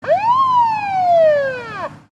Звуки полицейской сирены
Короткий звук сирены полиции (1 секунда)